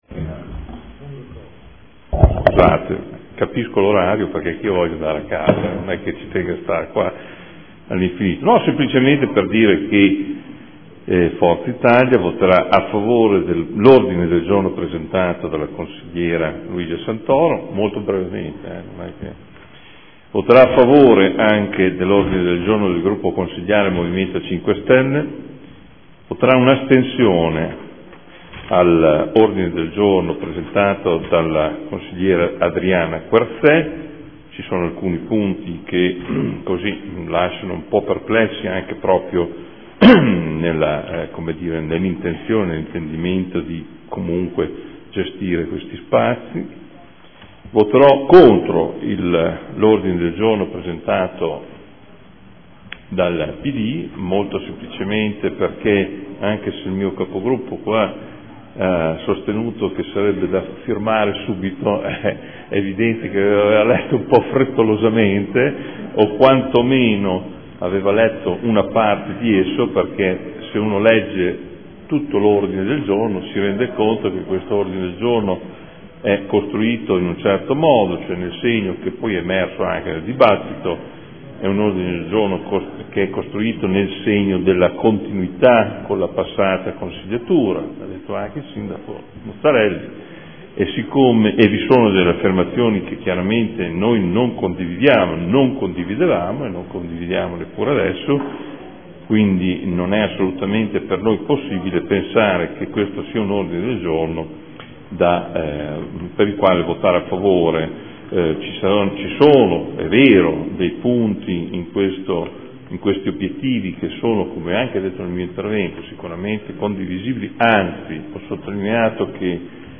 Seduta del 9/10/2014 Dibattito Sicurezza. Dichiarazione di voto.